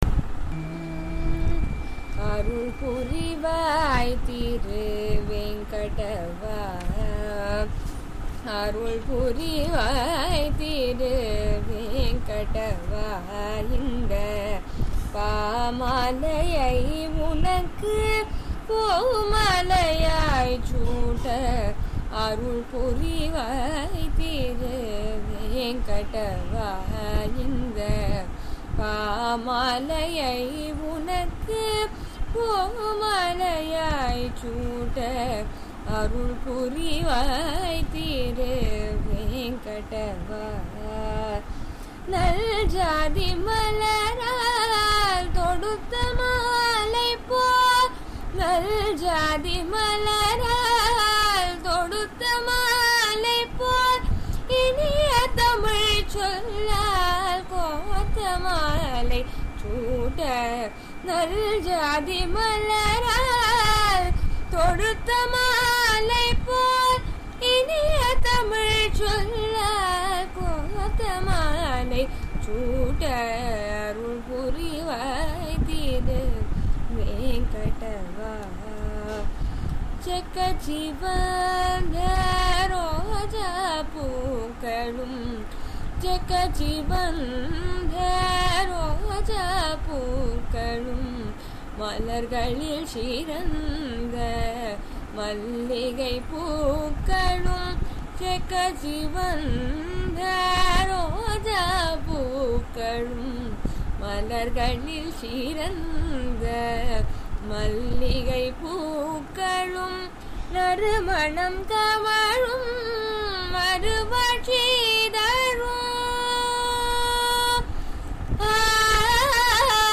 அருள் புரிவாய் திருவேங்கடவா ராகம்: ரஞ்சனி அருள் புரிவாய் திருவேங்கடவா இந்த பாமாலையை உனக்கு பூமாலையாய் சூட்ட ...